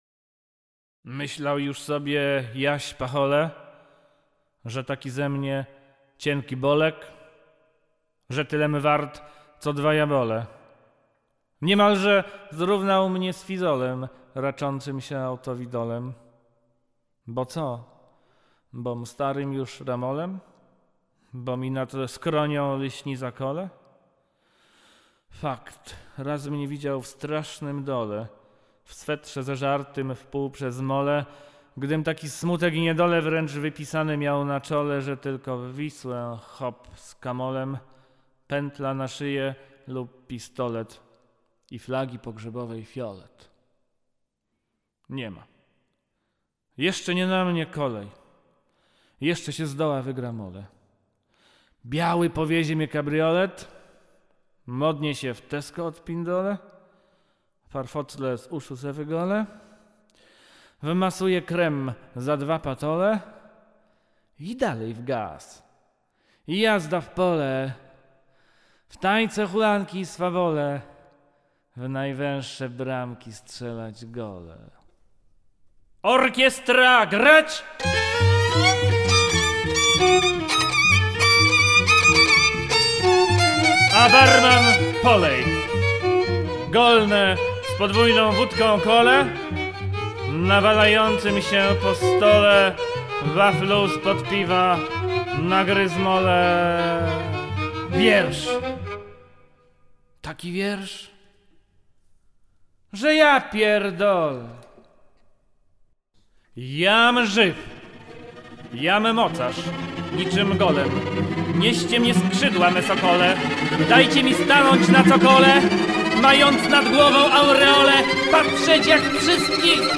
recytacje: